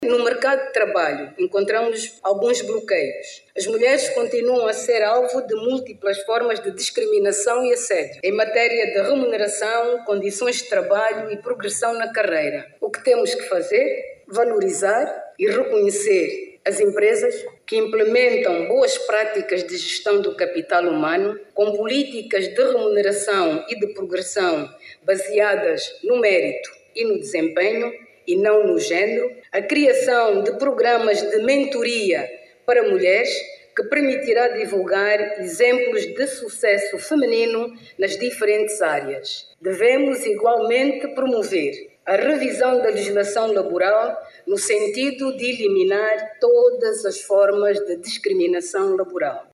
A Primeira da Dama da República, Ana Dias Lourenço defendeu, em Luanda, a revisão da lei do trabalho visando a eliminação de todas as formas de descriminação laboral. Ao intervir na Terceira edição do Congresso Internacional de Liderança Assertiva, Ana Dias Lourenço referiu que a necessidade se justifica porque as mulheres continuam a ser alvo de múltiplas formas de descriminação e assédio no mercado de trabalho em Angola.